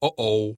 Звуки ой
Звук о оу у меня неприятности